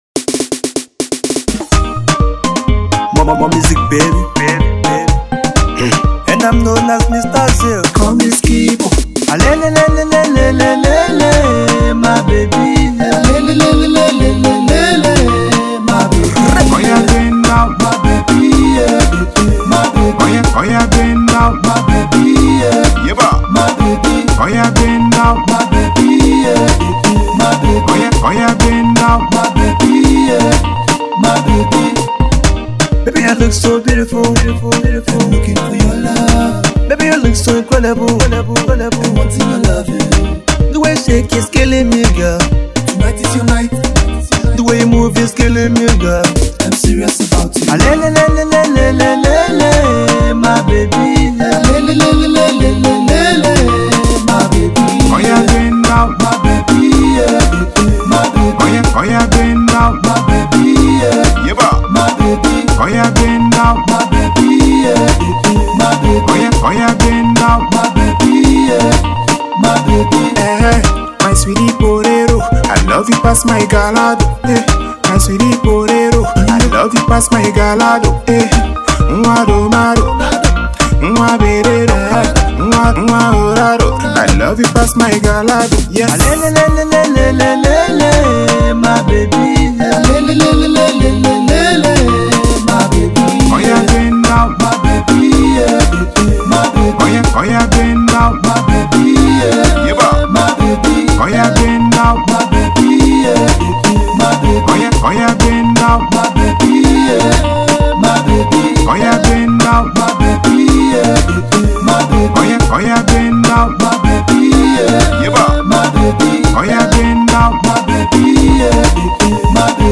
Highlife Music